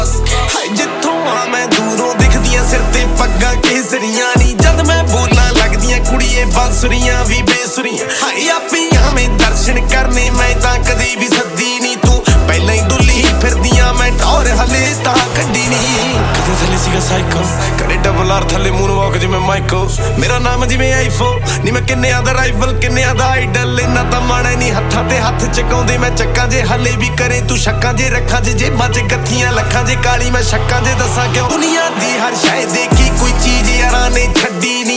Жанр: Рэп и хип-хоп